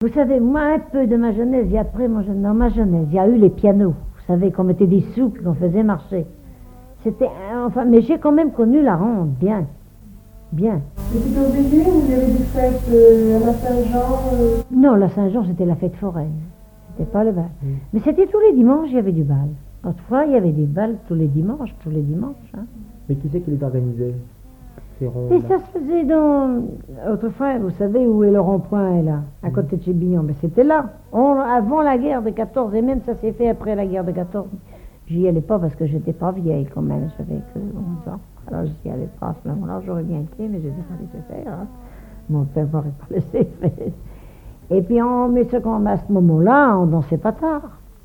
Mémoires et Patrimoines vivants - RaddO est une base de données d'archives iconographiques et sonores.
Témoignages sur les noces et les danses
Catégorie Témoignage